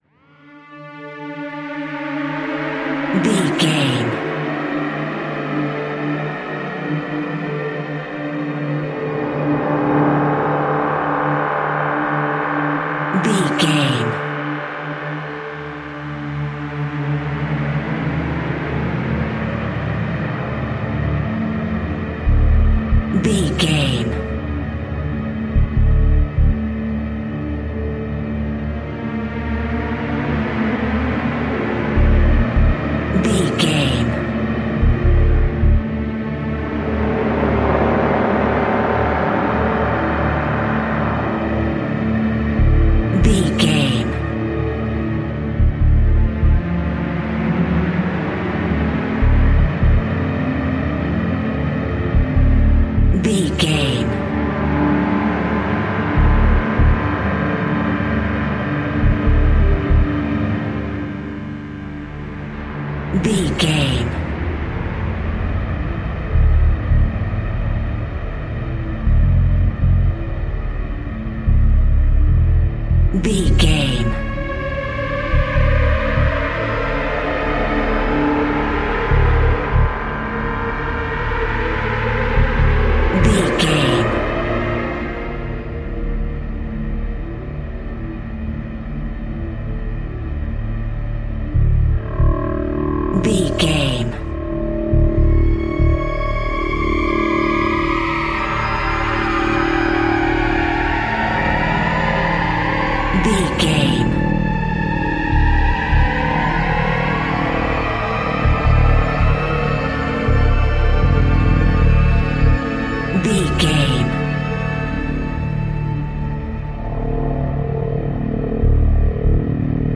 In-crescendo
Thriller
Atonal
Slow
ominous
dark
haunting
eerie
synthesiser
strings
creepy
horror music